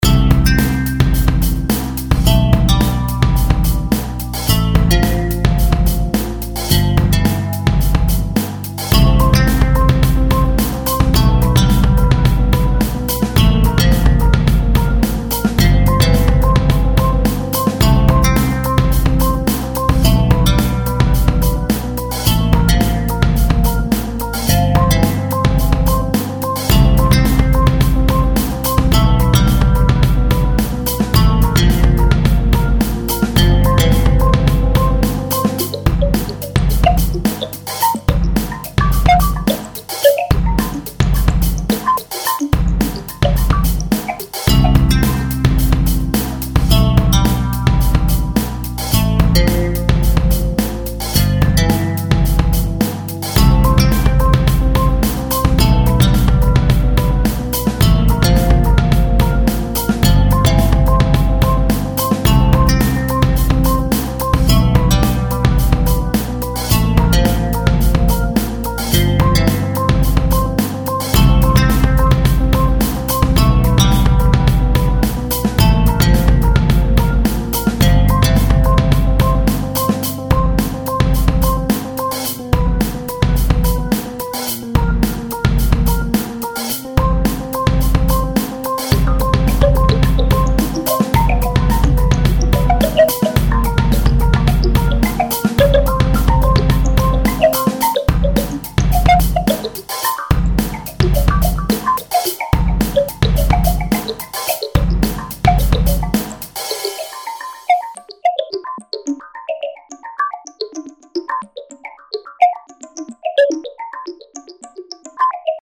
instumental
作ったときはこのループメロディヤバイと思ったけど最終的にはなんか爽やか風に。